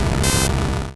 Cri de Magnéti dans Pokémon Rouge et Bleu.